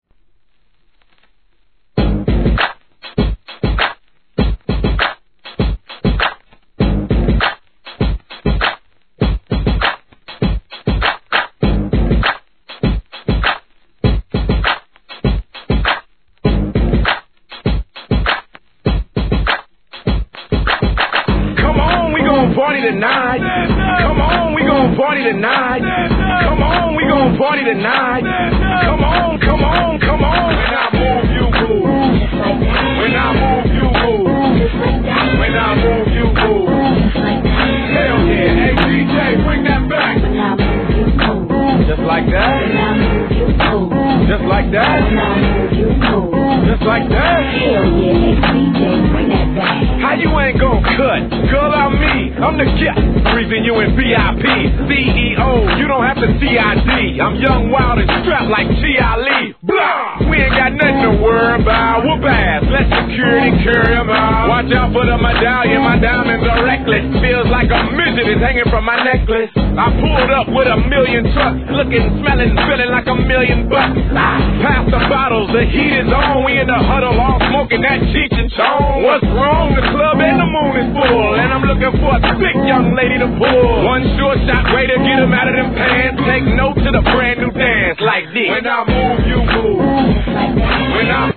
HIP HOP/R&B
INTROに加えBREAKを設けたかなりDJ USEな企画です!